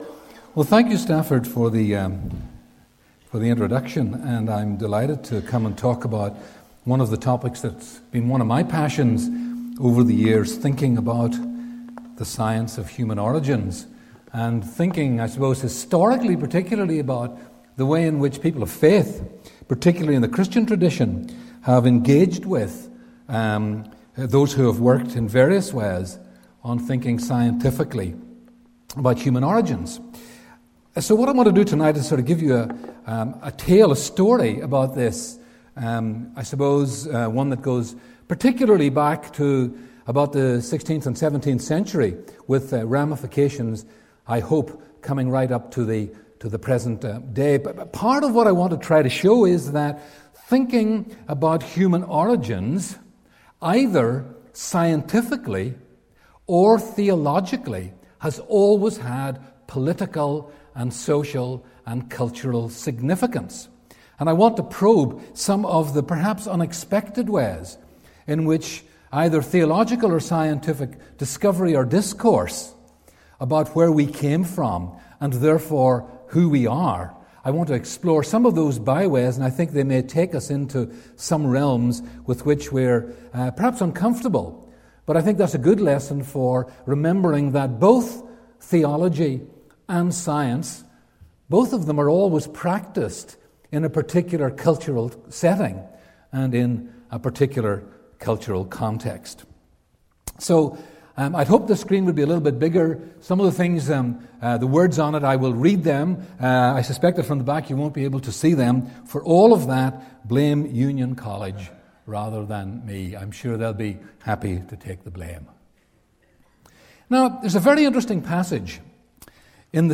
Seminar 4: Christianity and the science of human origins